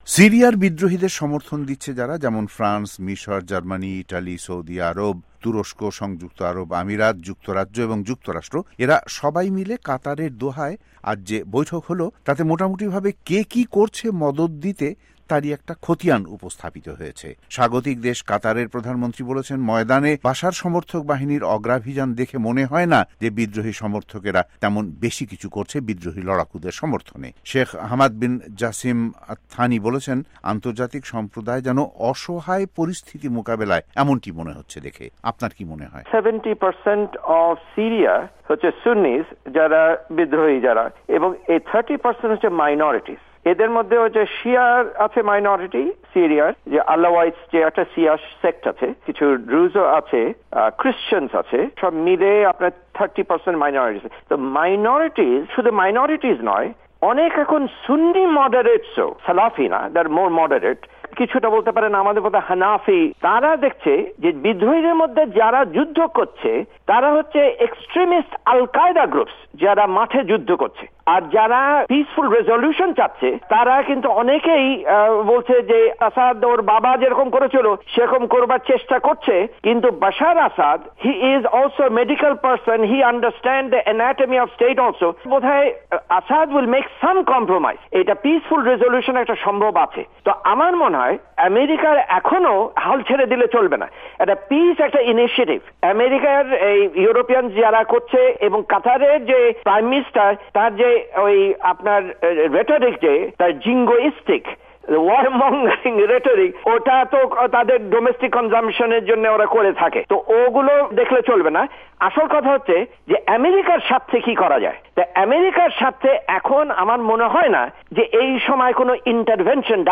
ভয়েস অফ এ্যামেরিকার বাংলা বিভাগের সঙ্গে এক সাক্ষাত্কারে